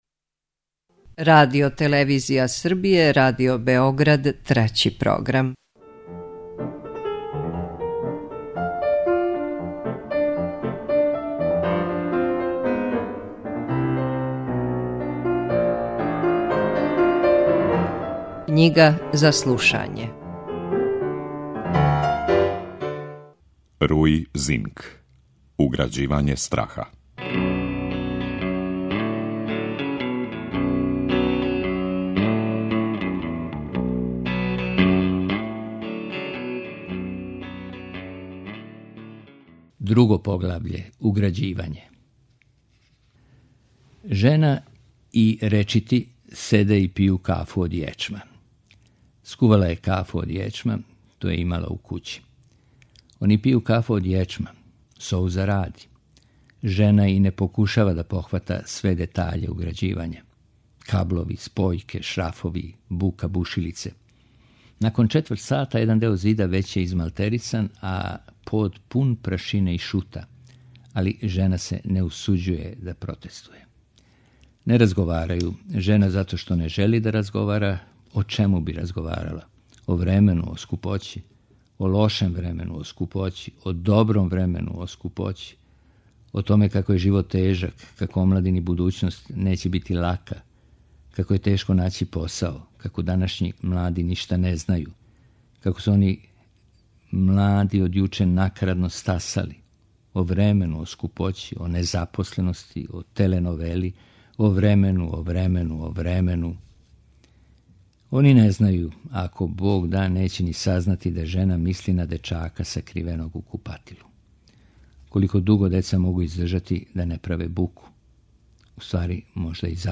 У емисији Књига за слушање, вечерас можете пратити други наставак романа Уграђивање страха, чији је аутор португалски писац Руи Зинк.